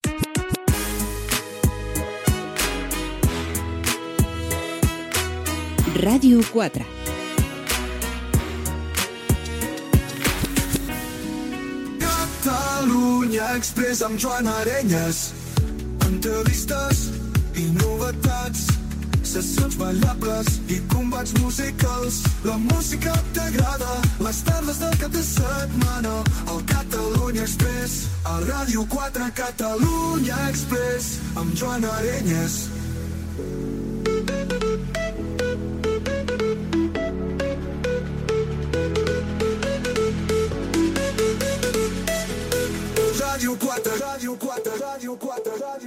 Indicatiu de la ràdio, careta del programa.
Salutació i sumari de les dues hores, tema musical.